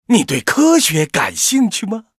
文件 文件历史 文件用途 全域文件用途 Rt_amb_02.ogg （Ogg Vorbis声音文件，长度2.2秒，107 kbps，文件大小：28 KB） 源地址:游戏语音 文件历史 点击某个日期/时间查看对应时刻的文件。 日期/时间 缩略图 大小 用户 备注 当前 2018年5月20日 (日) 14:51 2.2秒 （28 KB） 地下城与勇士  （ 留言 | 贡献 ） 分类:诺顿·马西莫格 分类:地下城与勇士 源地址:游戏语音 您不可以覆盖此文件。